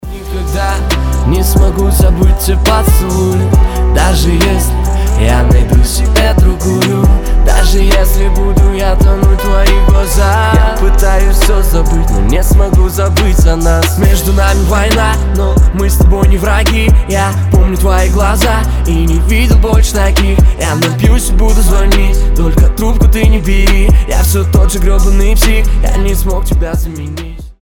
• Качество: 320, Stereo
лирика